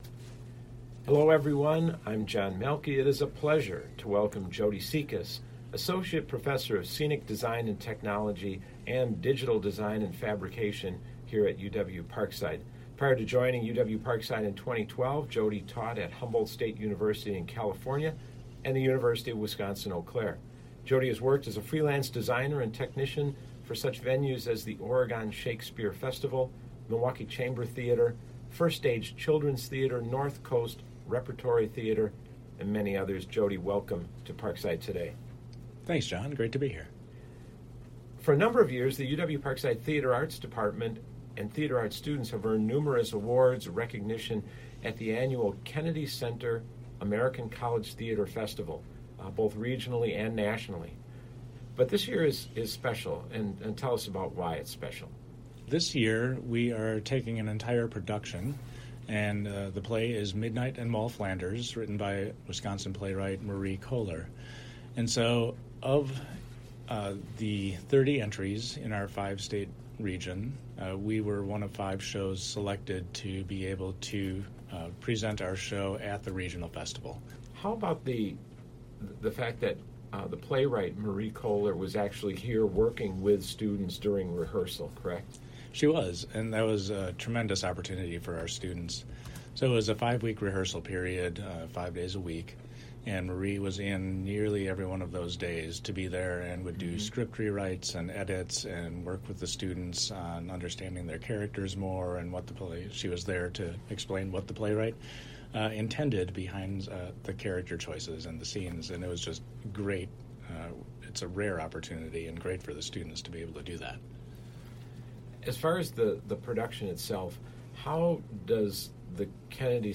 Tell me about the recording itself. This interview originally aired on WIPZ 101.5 FM on Tuesday, January 8, at 4 p.m.